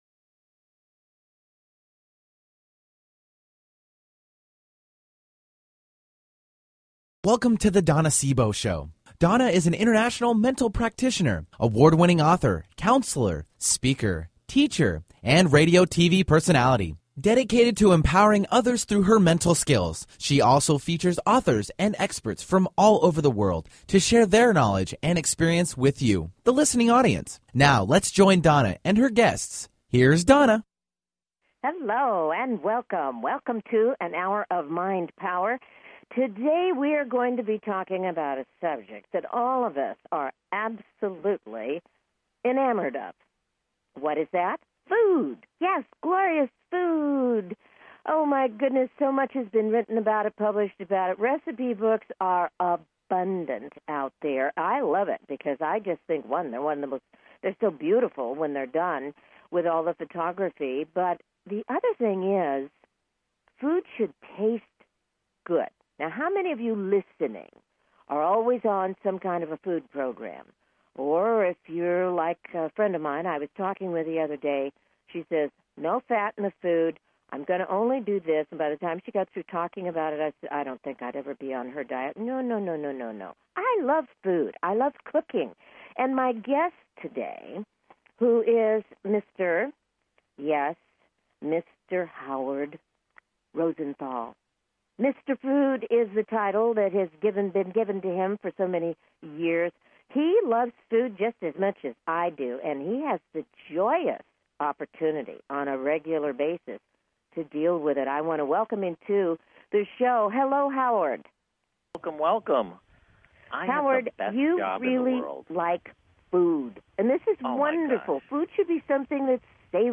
Talk Show Episode
Her interviews embody a golden voice that shines with passion, purpose, sincerity and humor.
Tune in for an "Hour of Mind Power". Callers are welcome to call in for a live on air psychic reading during the second half hour of each show.